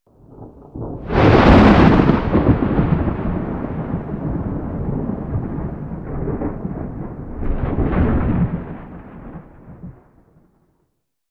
thundernew1.ogg